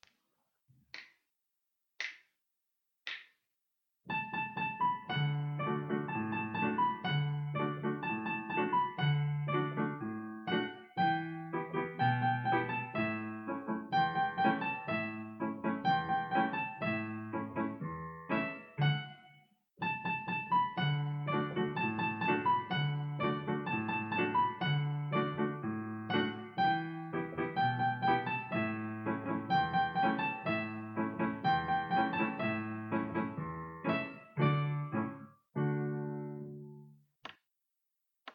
L’accompagnement et la a mélodie sont joués au piano pour faciliter le chant.
Il y a des chansons enregistrées à vitesse lente pour les MS et d’autres plus rapides pour les GS.